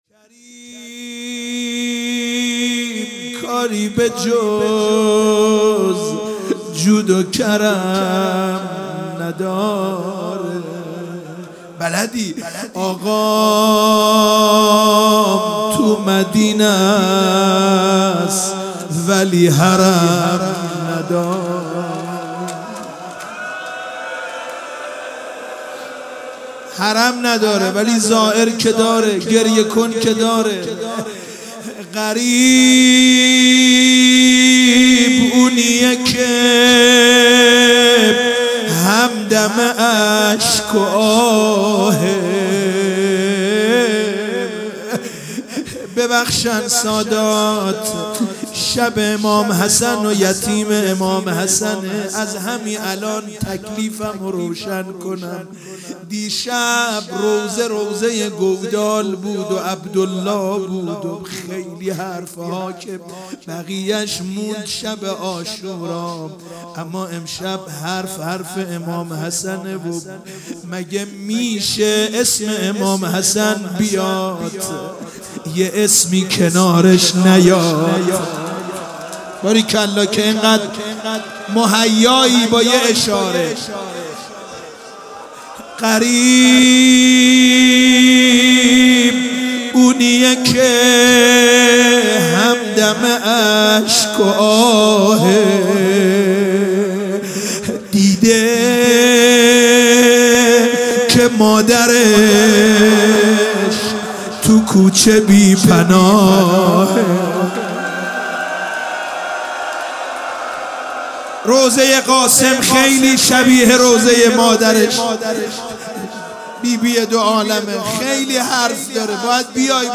شب ششم محرم 96 - روضه